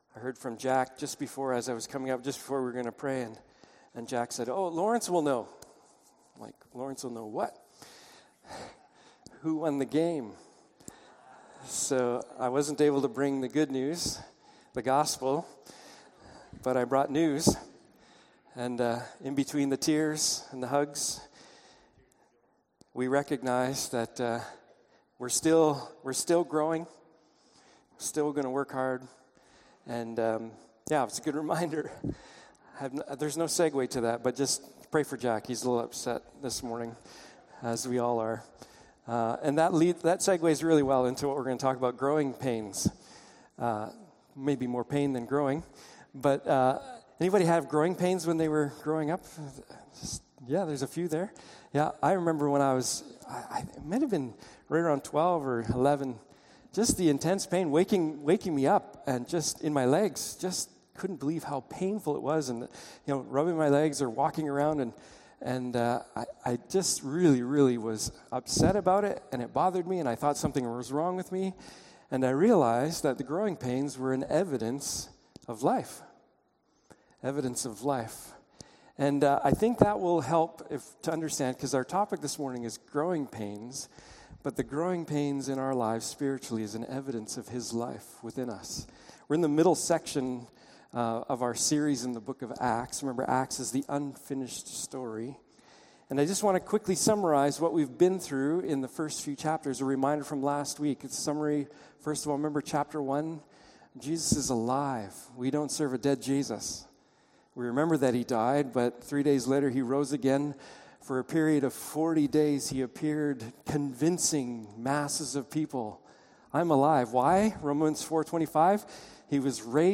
Passage: Acts 4:24-31, Acts 5:17-26, Psalm 2 Service Type: Morning Service